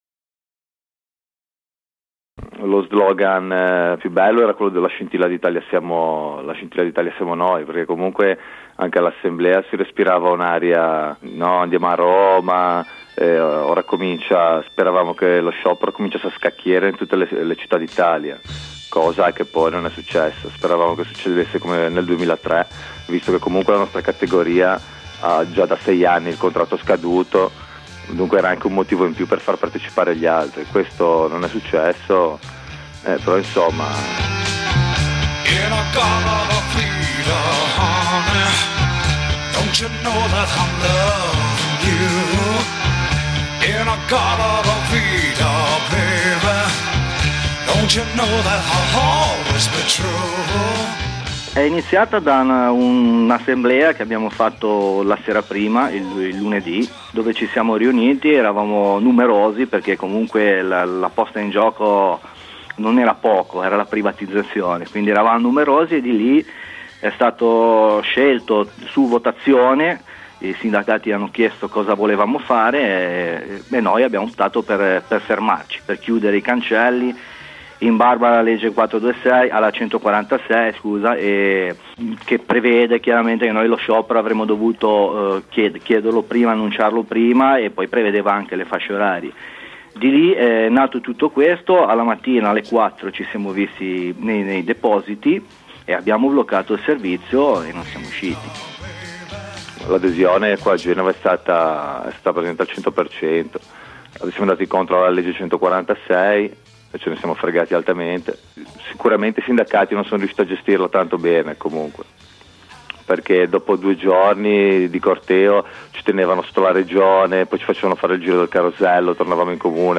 A freddo abbiamo voluto ripercorrere le vicende di quella settimana di lotta, a suo modo paradigmatica, con alcuni lavoratori protagonisti dello sciopero e altri solidali.